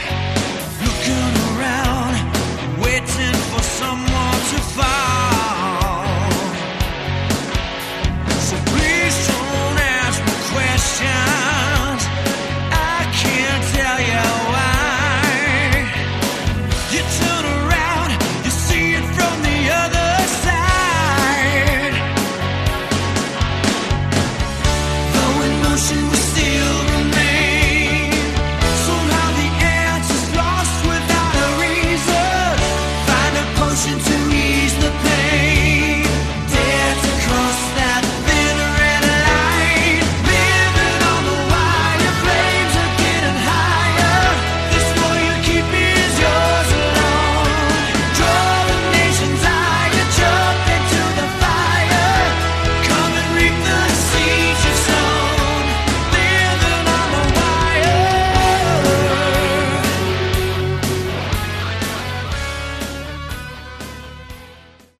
Category: Melodic Metal
guitar, keyboards and vocals
drums and vocals